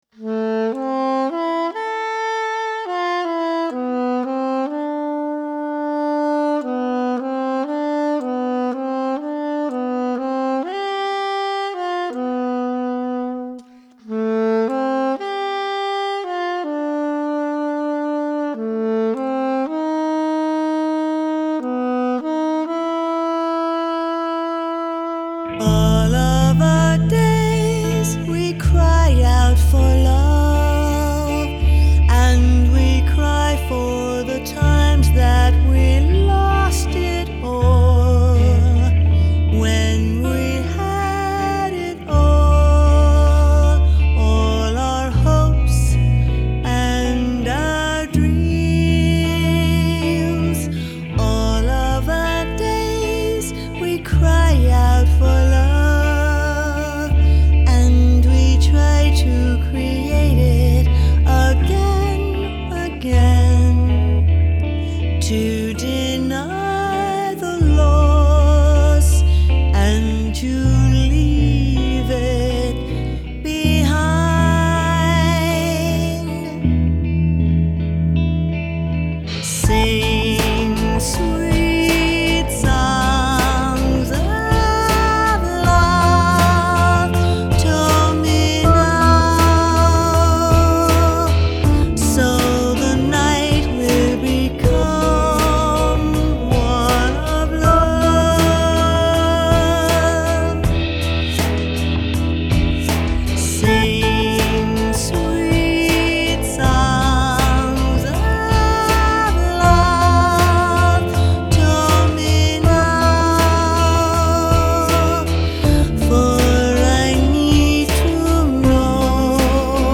Новая волна, рок-музыка